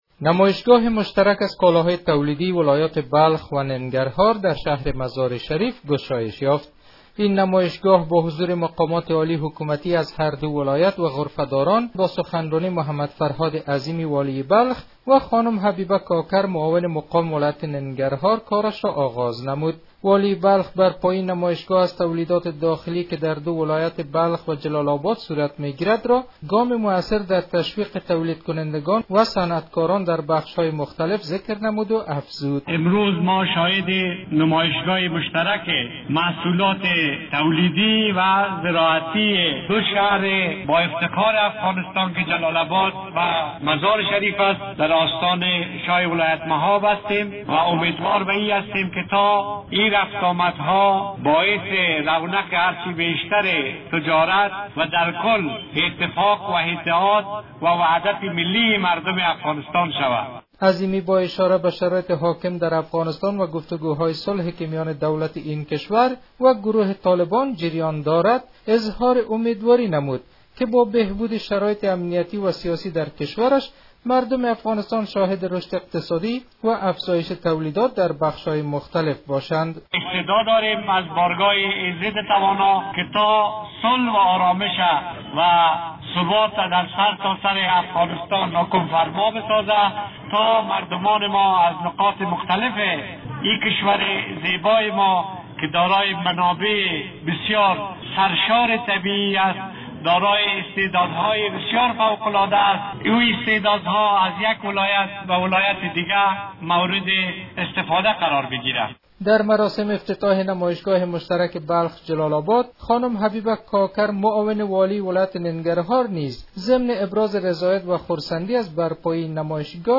جزئیات بیشتر در گزارش